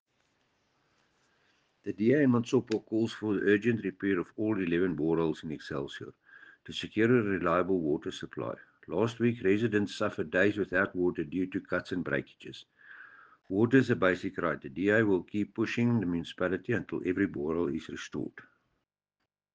Afrikaans soundbites by Cllr Dewald Hattingh and